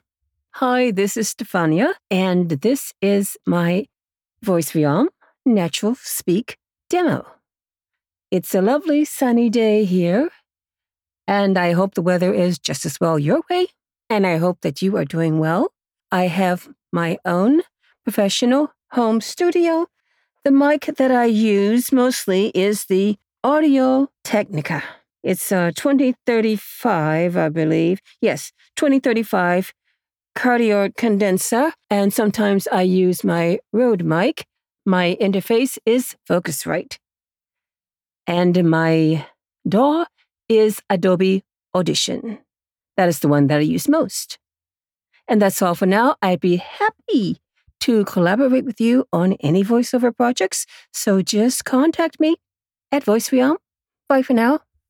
Female
Adult (30-50), Older Sound (50+)
Natural Speak Self Intro